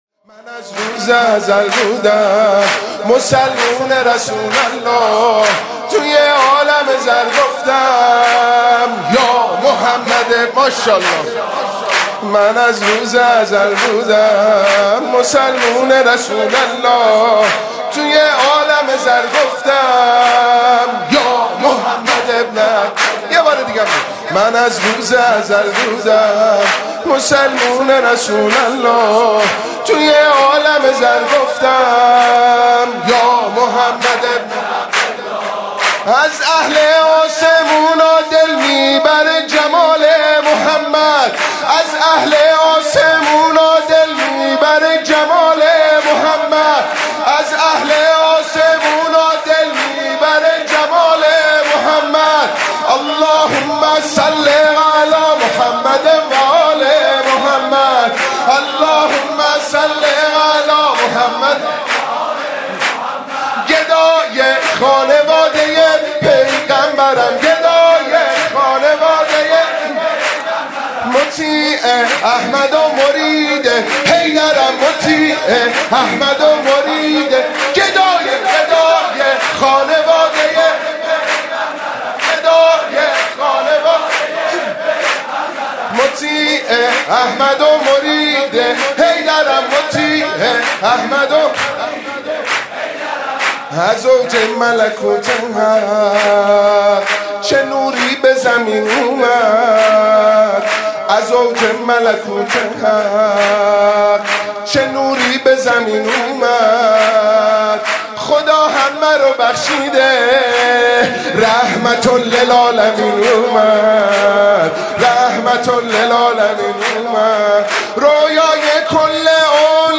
ویژه نامه میلاد باسعادت حضرت رسول اکرم(صلی الله علیه و آله) و امام جعفرصادق(علیه السلام) شامل زندگینامه ، تصاویر ،احادیث و مولودی خوانی های صوتی منتشر می شود.